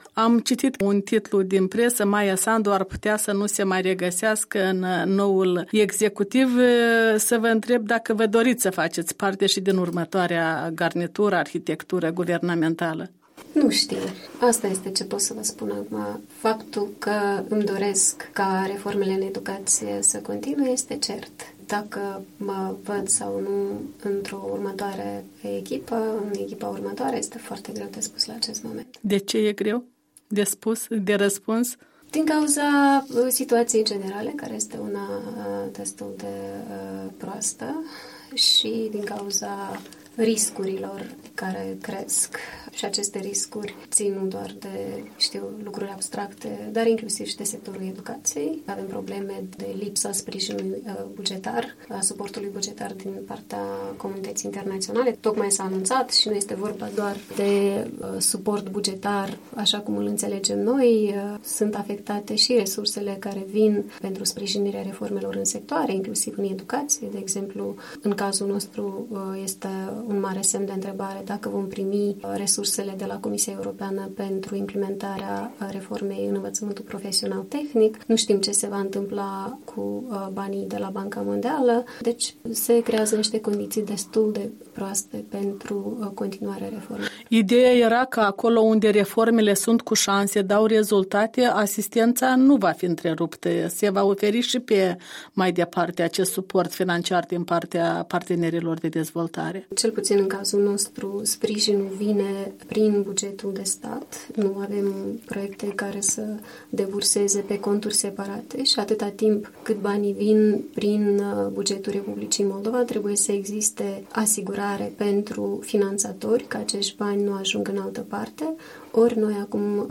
Interviu cu ministrul demisionar al învățământului de la Chișinău